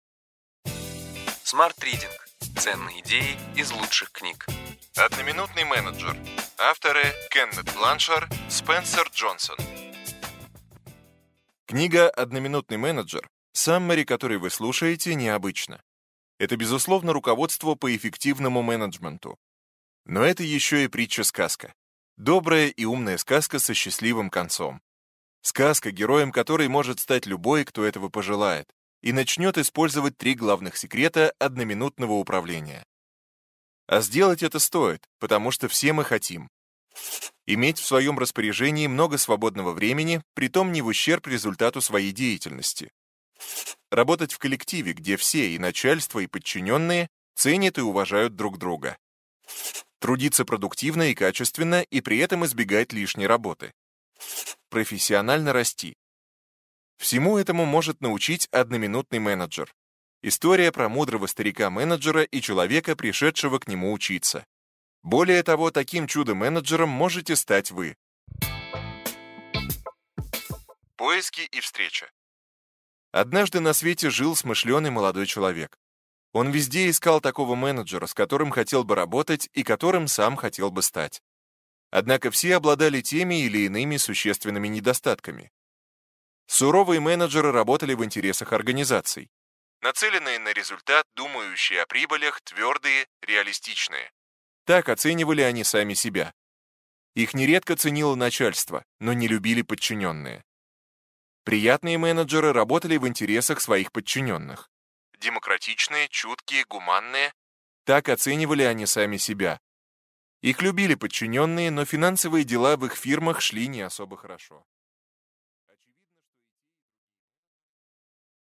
Аудиокнига Ключевые идеи книги: Одноминутный менеджер.